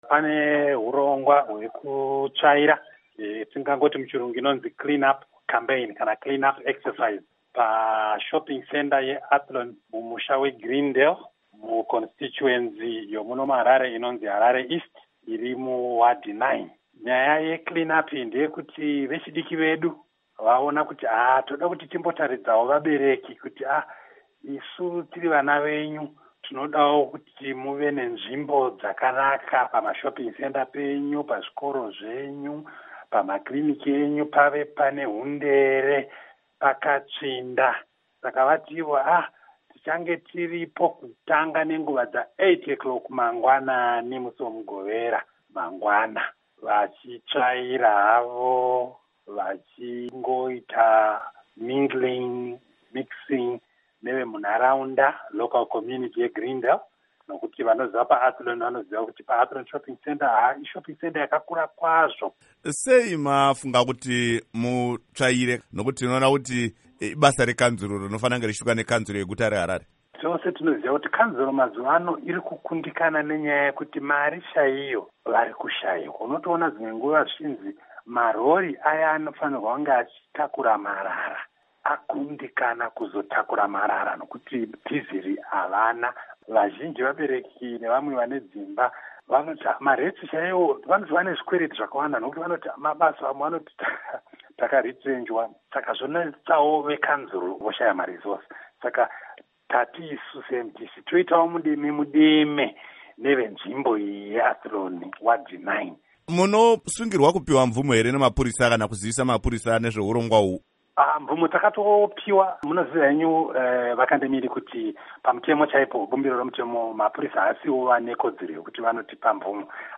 Hurukuro naVaObert Gutu